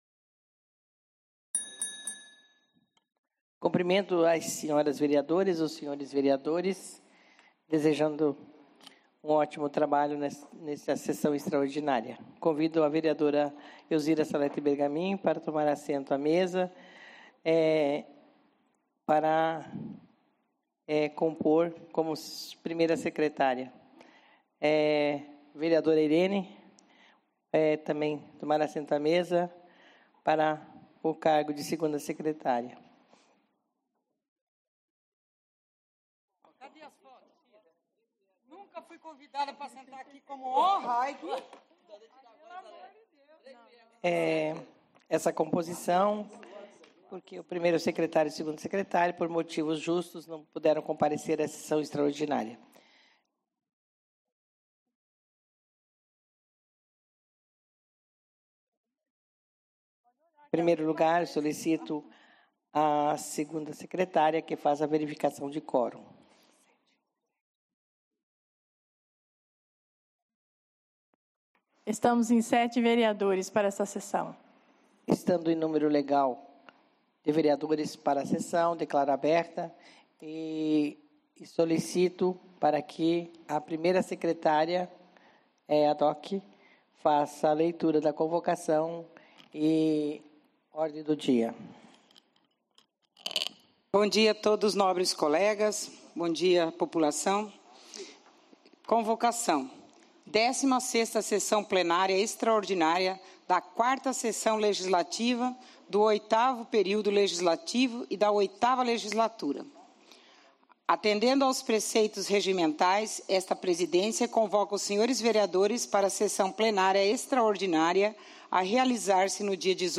Áudio da sessão extraordinária realizada no dia 18/10/2016 as 08 horas no Plenário Henrique Simionatto.
18/10/2016 - Sessão Extraordinária